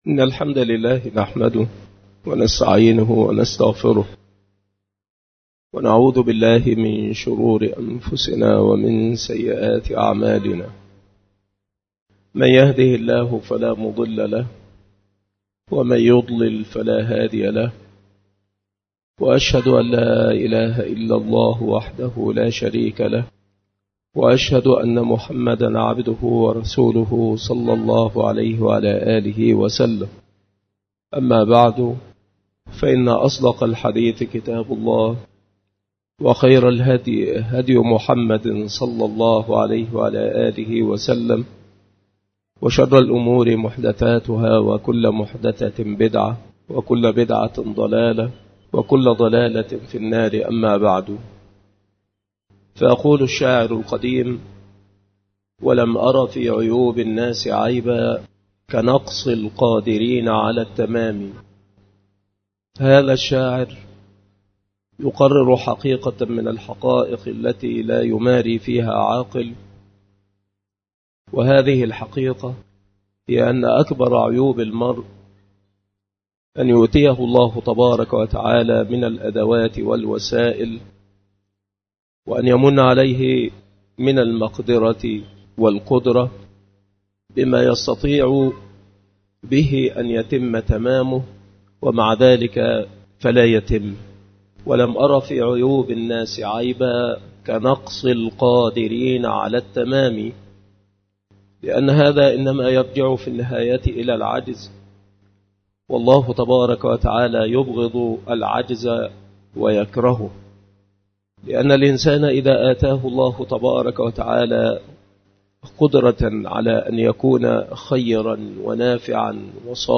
المحاضرة
بالمسجد الشرقي - سبك الأحد - أشمون - محافظة المنوفية - مصر